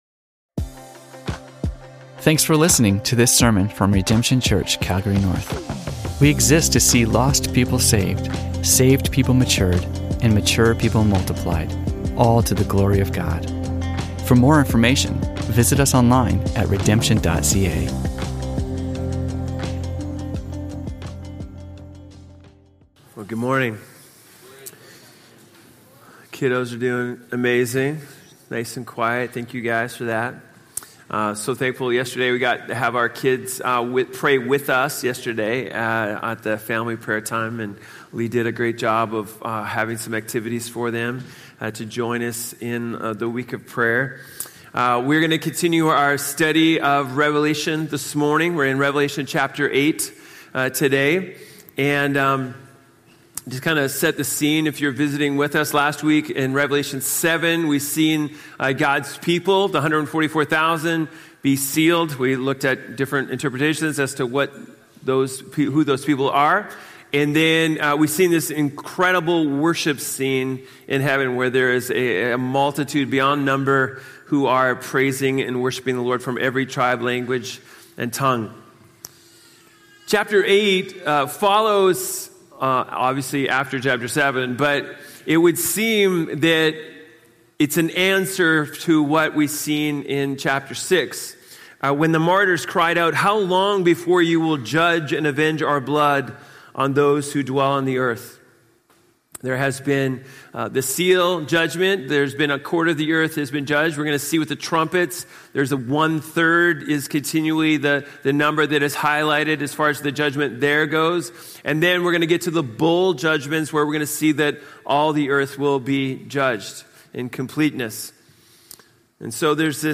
Sermons from Redemption Church Calgary North